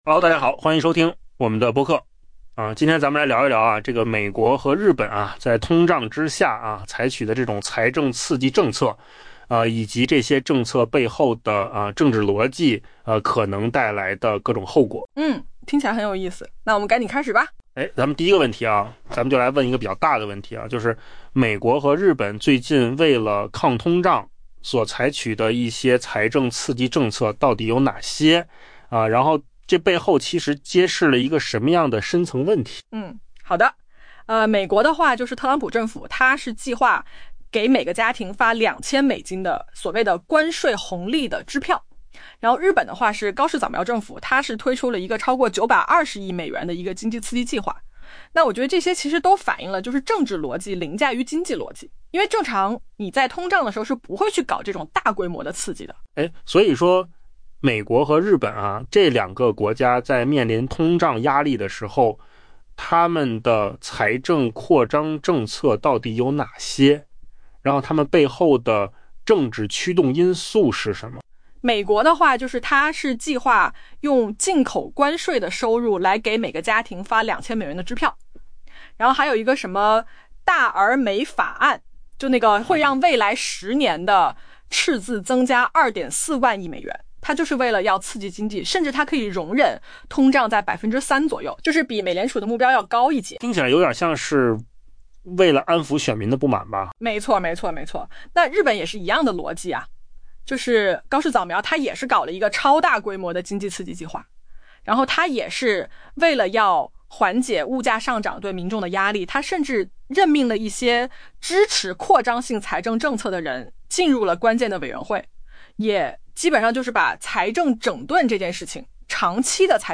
AI 播客：换个方式听新闻 下载 mp3 音频由扣子空间生成 美国与日本正在采用一种新颖的抗通胀工具：财政刺激。